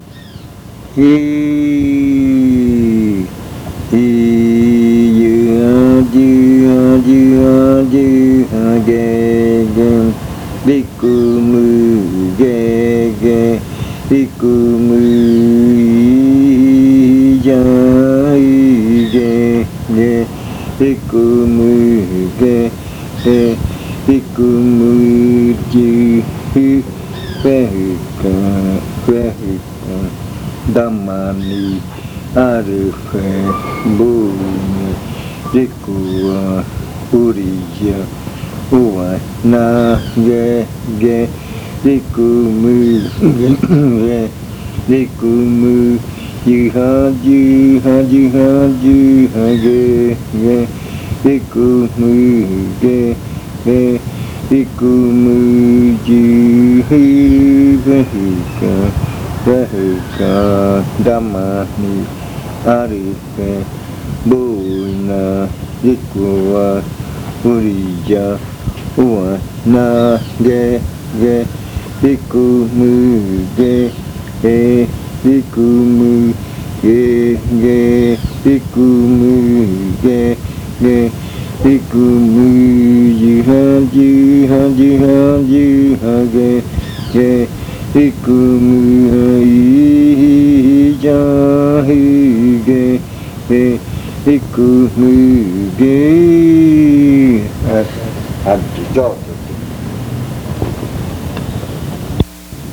Leticia, Amazonas
Dice: “Se van los loritos trepándose, haciendo bulla en su madriguera para dormir”. Canto con adivinanza, dice la canción que los loritos están en su madriguera, los cogen para mascotas en las casas, por la orilla del rio surgen; así dice la adivinanza.
Chant with a riddle, the chant says that the parrots are in their burrow, they take them for pets in the houses, along the river bank they emerge; thus says the riddle.